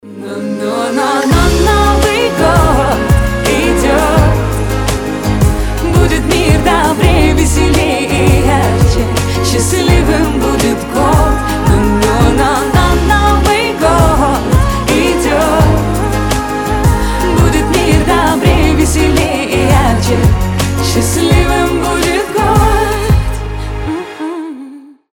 • Качество: 320, Stereo
красивые
женский голос
медленные
добрые